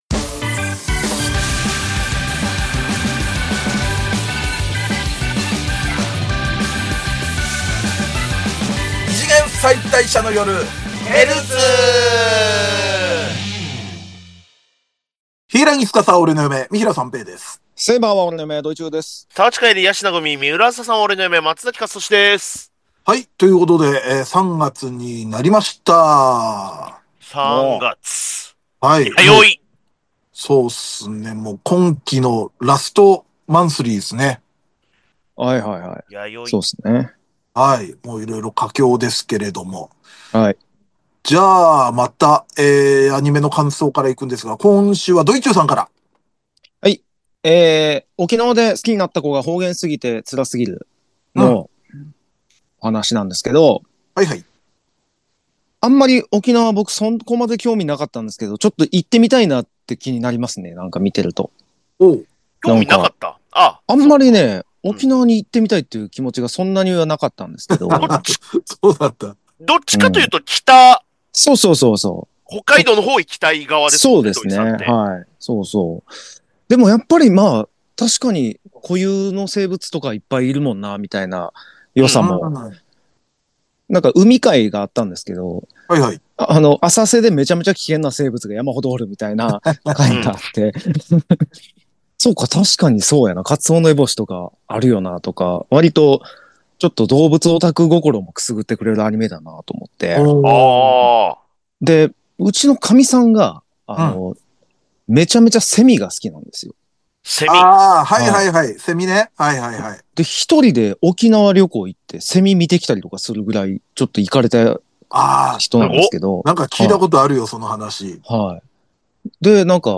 二次元妻帯者芸人によるキャラ萌え中心ポッドキャストラジオ